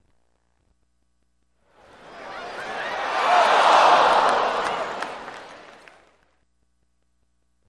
Crowd Astonished Reaction Effect